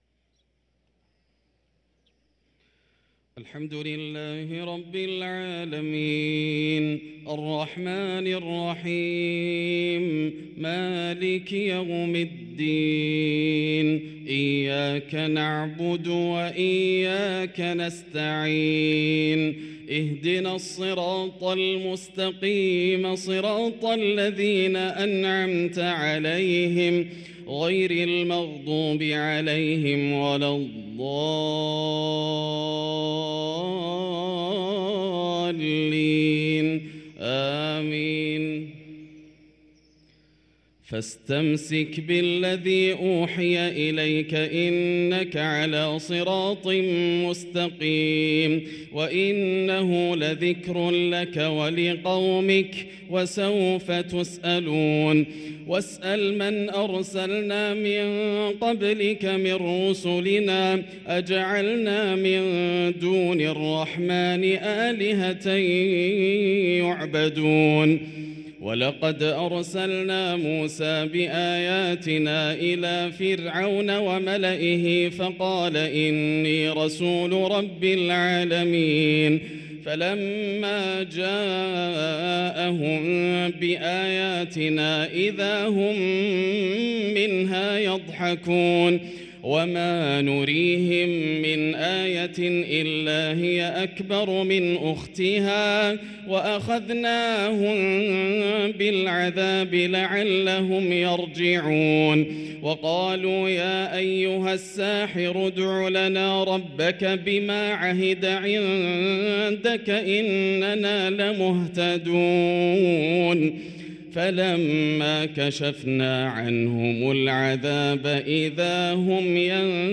صلاة المغرب للقارئ ياسر الدوسري 26 شعبان 1444 هـ
تِلَاوَات الْحَرَمَيْن .